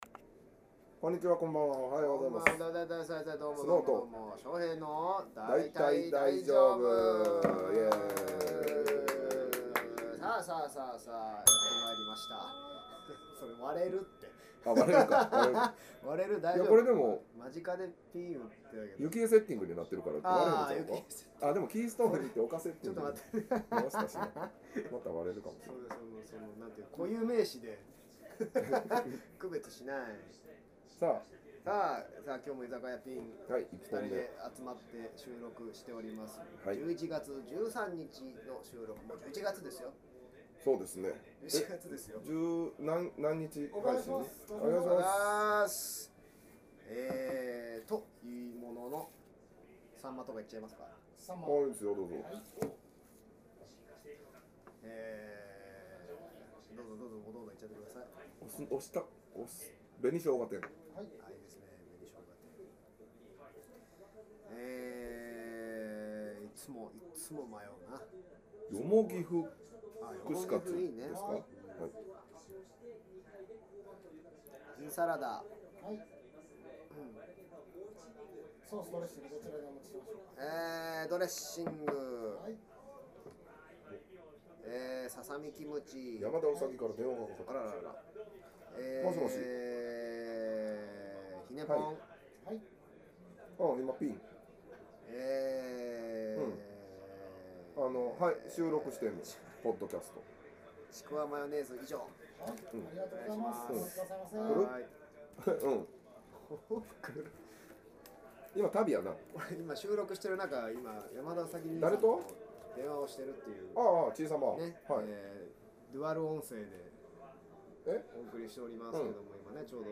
（１１月１３日収録①）さーて、今月も「ぴん」にて話しますよー。